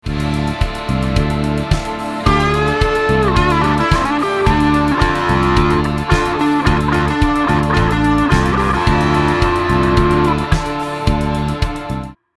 which uses the Dorian mode
anotherbrick_solo